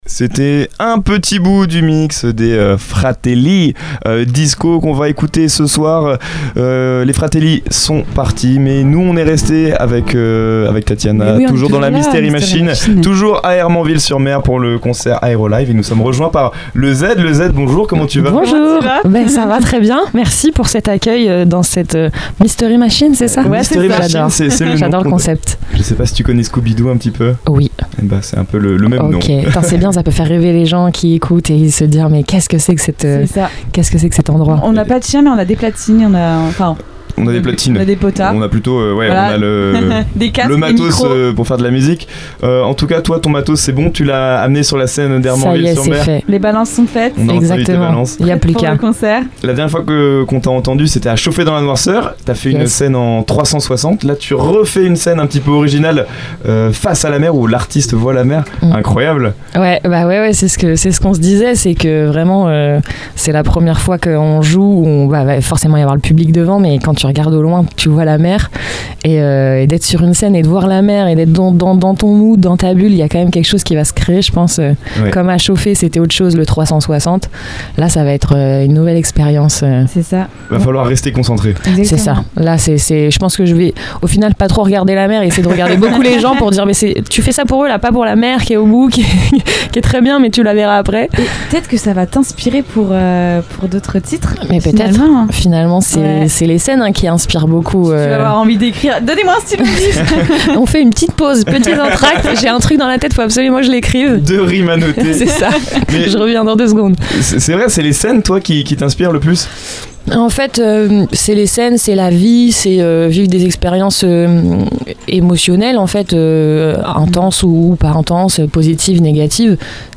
Dans cet épisode, la Mystery Machine pose ses micros à Hermanville-sur-Mer à l’occasion de l’événement Aérolive, organisé par la TFT.